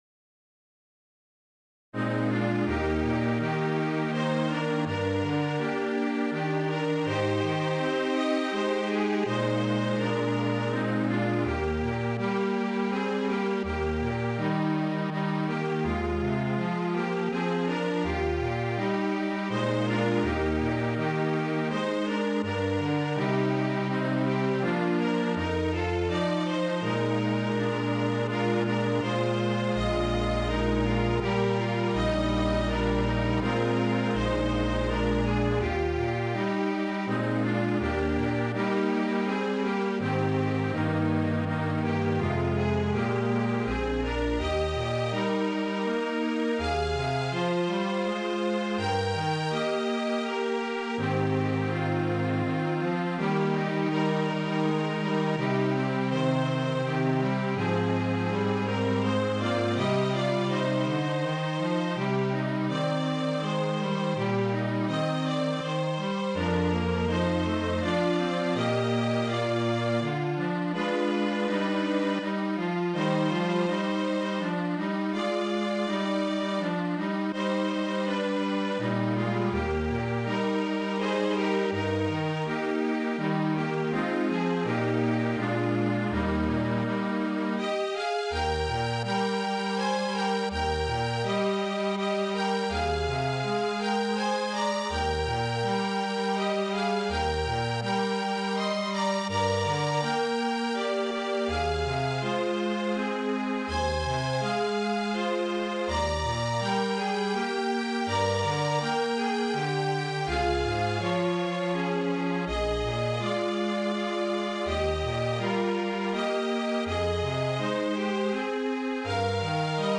As the Dew from Heaven Distilling, piano solo
Voicing/Instrumentation: Piano Solo We also have other 5 arrangements of " We Are Sowing ".